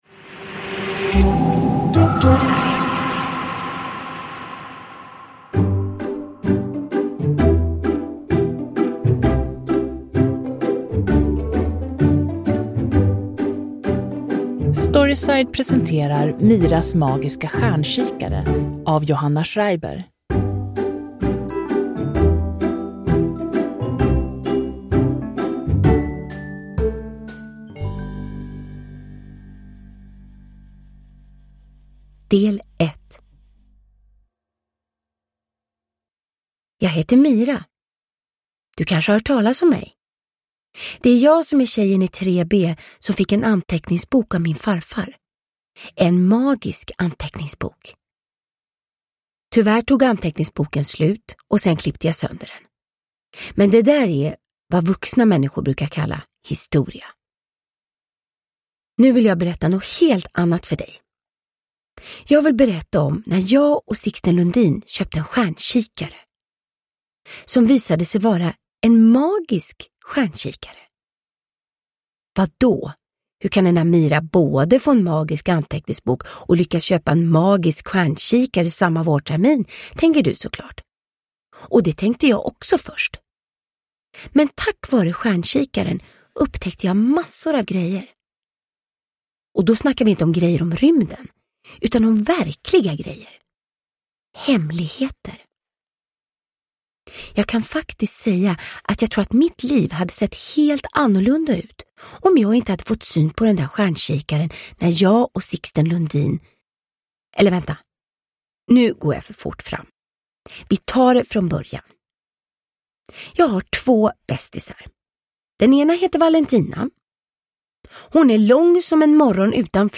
Miras magiska stjärnkikare – Ljudbok – Laddas ner
Uppläsare: Vanna Rosenberg